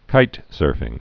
(kītsûrfĭng)